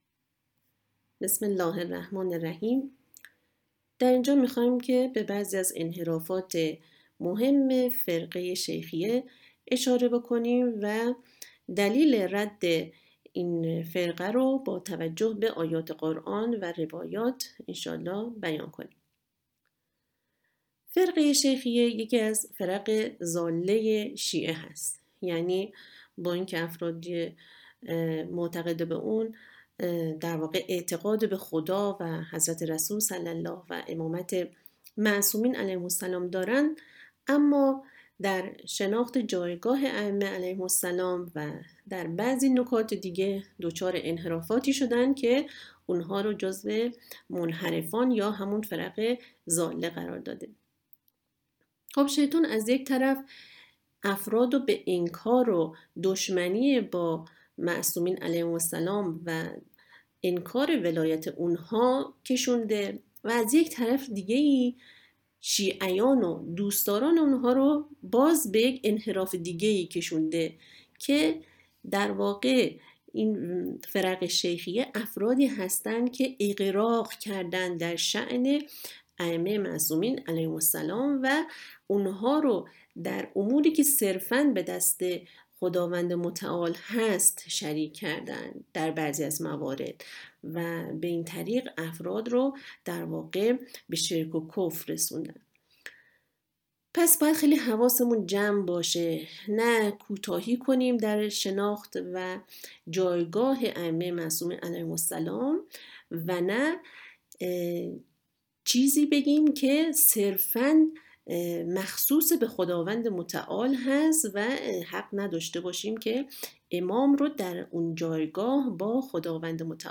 متن سخنرانی- جلسه اول: بسمه تعالی رد فرقه شیخیه قسمت اول معاد توضیحاتی راجع به فرقه شیخیه: فرقه شیخیه یکی از فرق ضاله شیعه است.